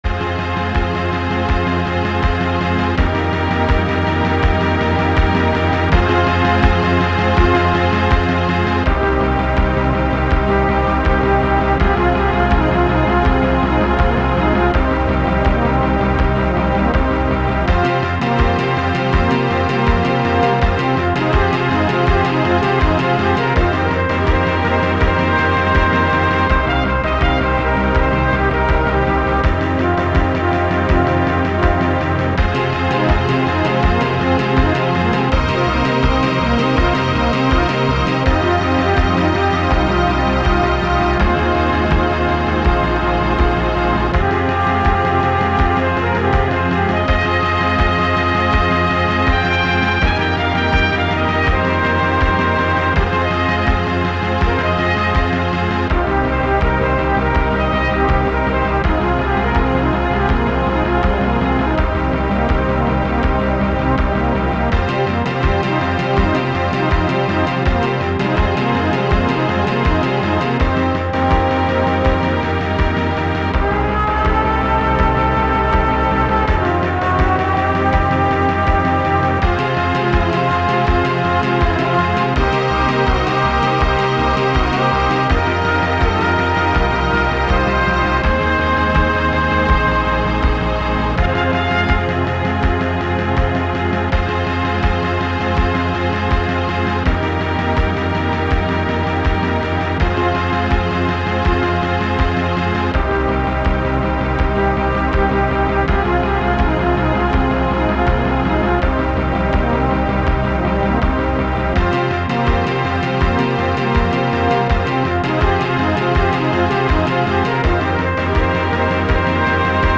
High-quality mp3s that are identical to the game's music.